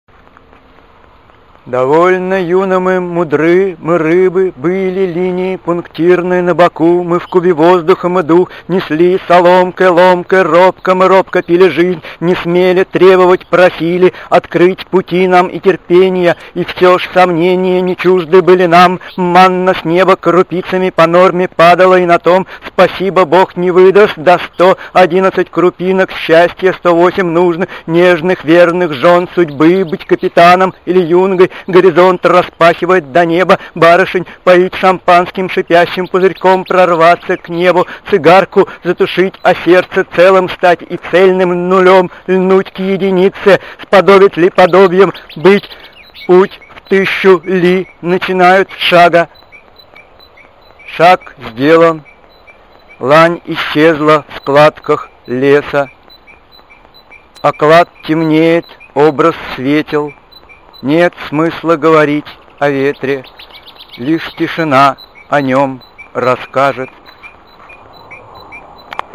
записи своих стихов